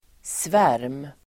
Uttal: [svär:m]